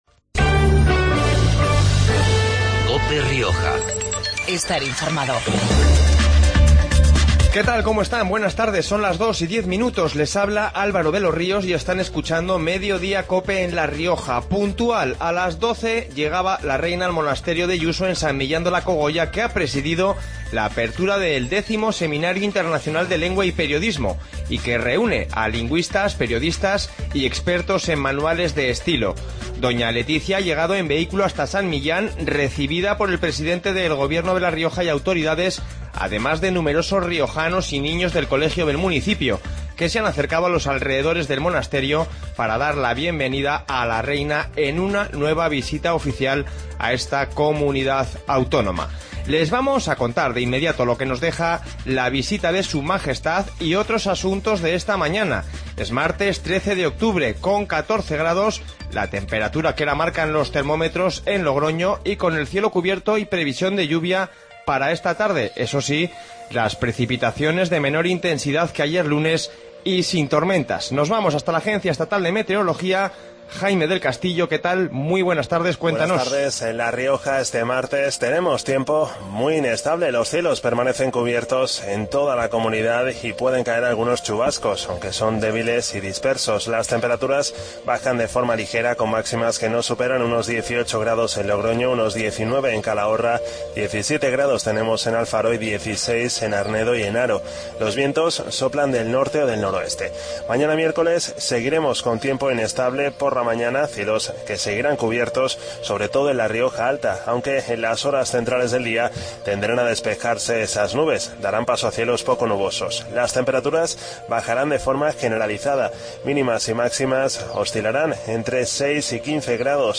Informativo Mediodia en La Rioja 13-10-15